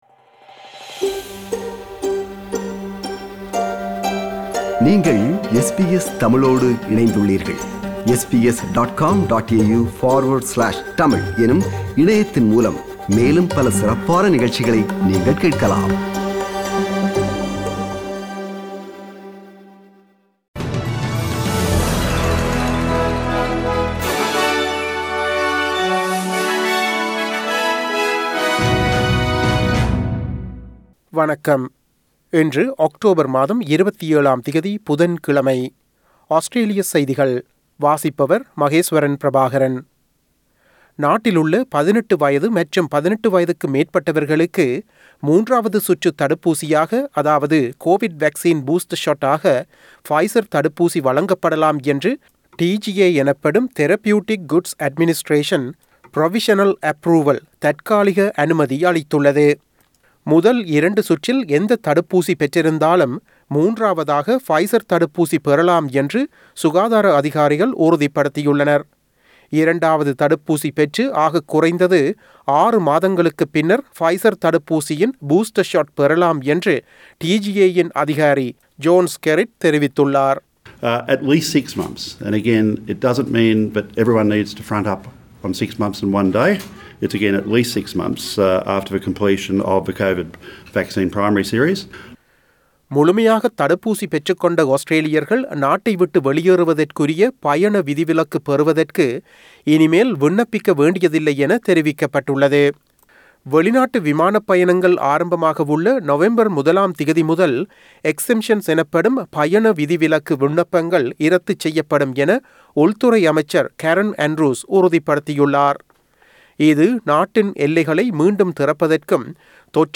Australian news bulletin for Wednesday 27 October 2021.